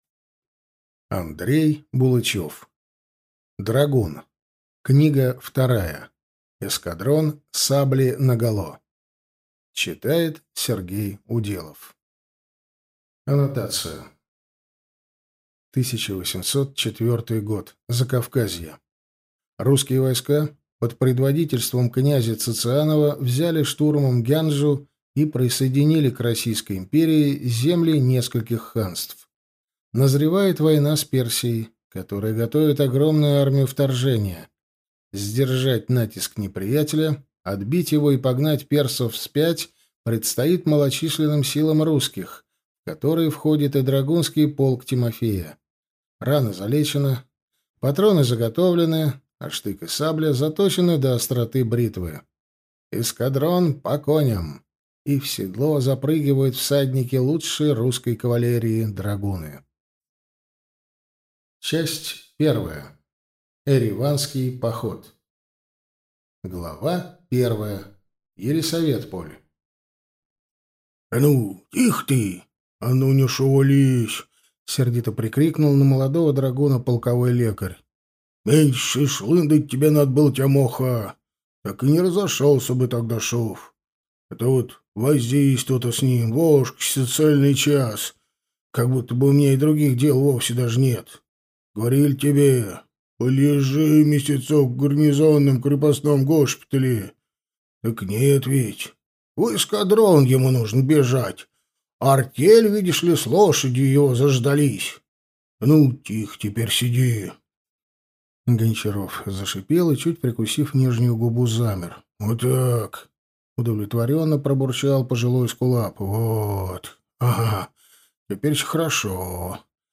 Аудиокнига Эскадрон, сабли наголо!